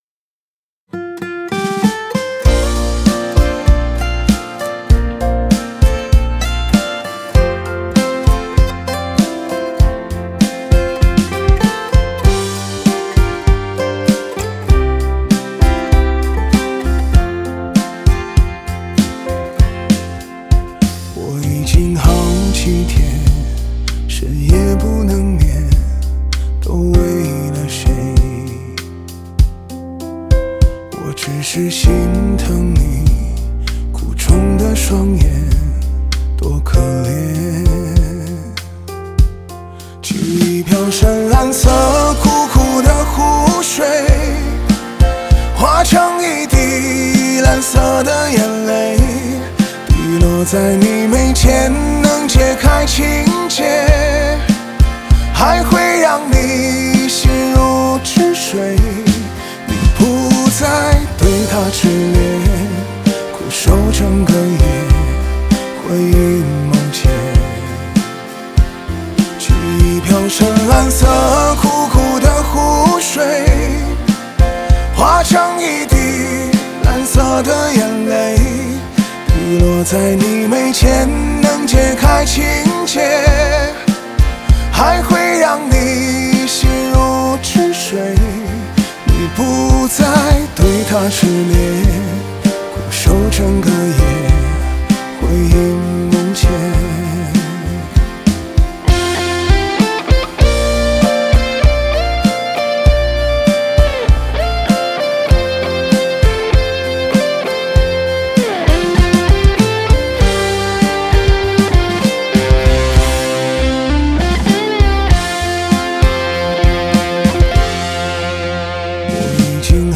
吉他Guitar